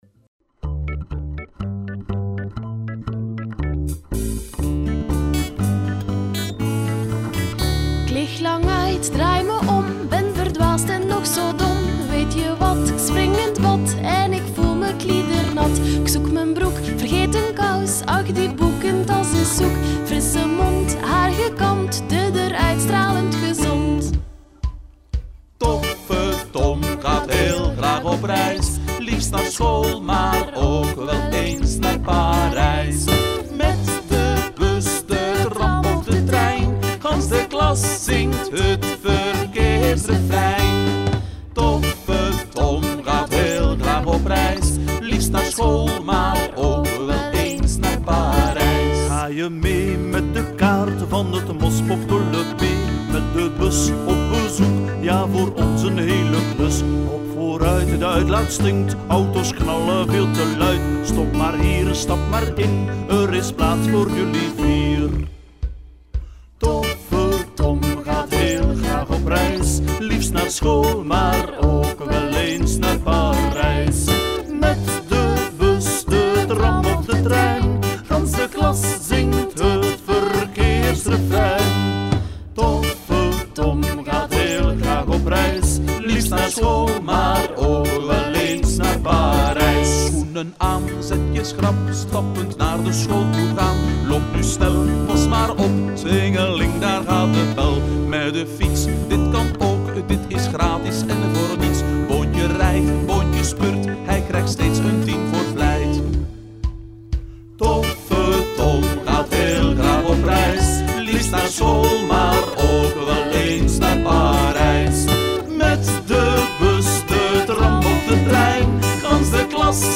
Zingen jullie mee met dit vrolijke lied over duurzame mobiliteit?